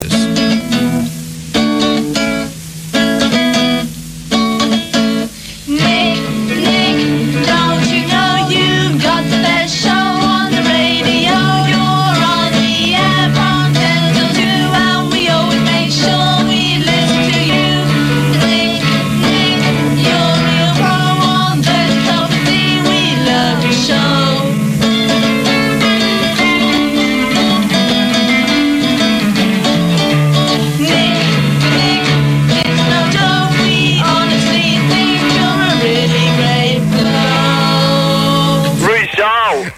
These were made by fans of his show.